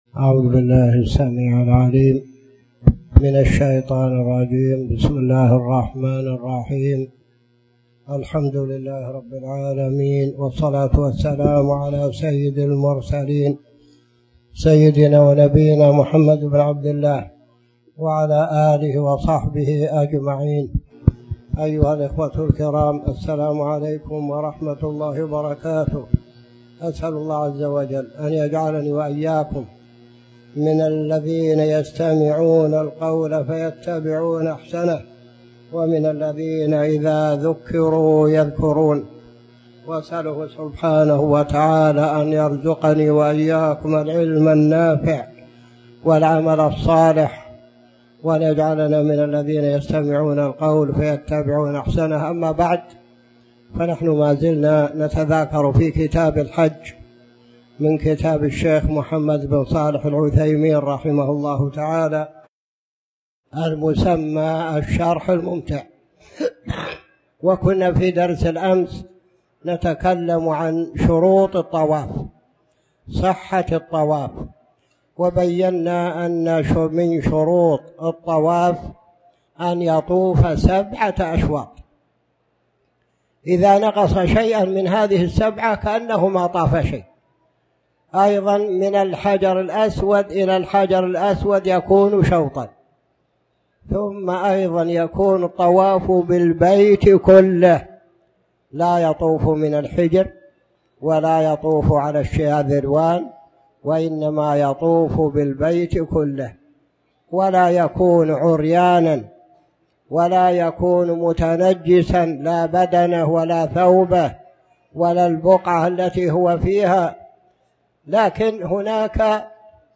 تاريخ النشر ٢٦ ذو الحجة ١٤٣٨ هـ المكان: المسجد الحرام الشيخ